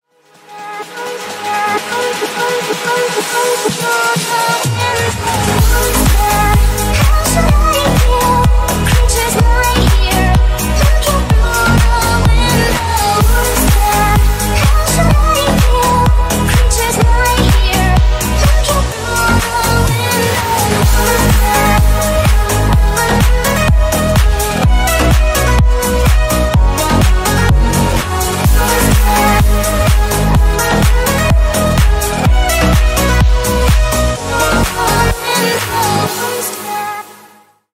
Ремикс # Поп Музыка
спокойные